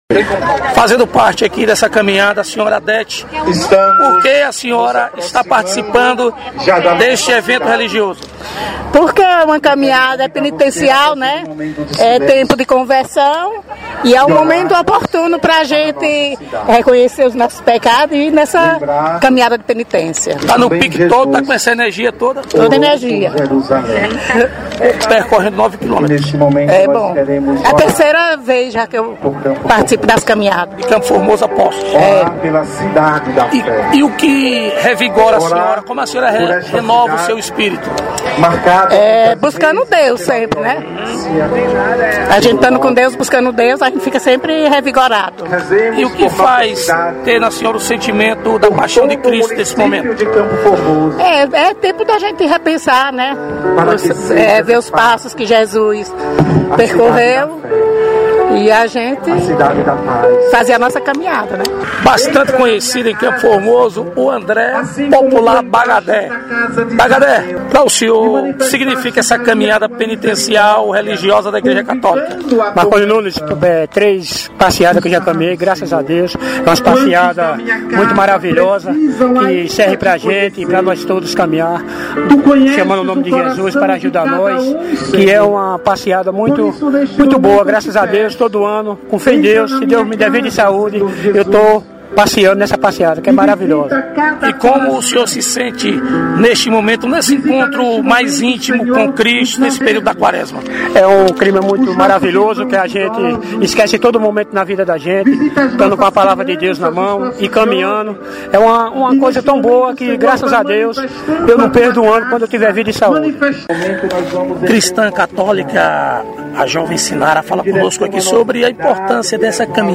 Reportagem: Caminhada penitencial – Quaresma